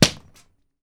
Claps
SLAP A    -S.WAV